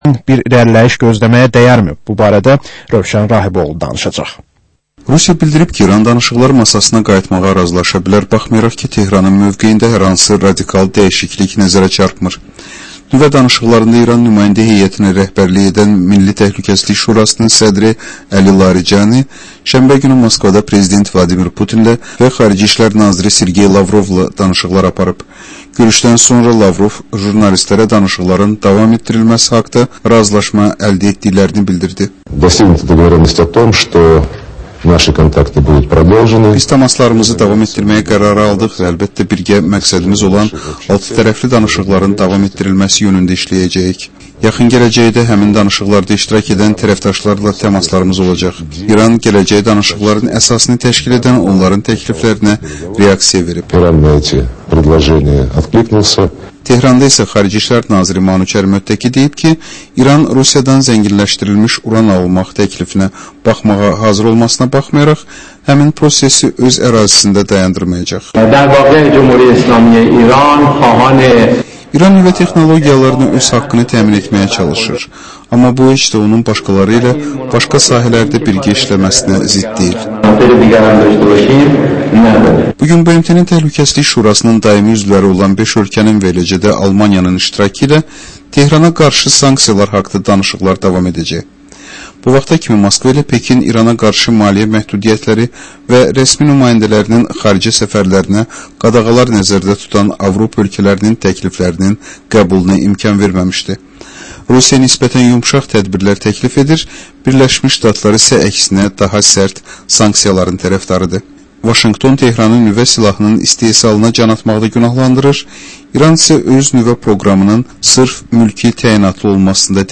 Həftənin mədəniyyər xəbərləri, reportajlar, müsahibələr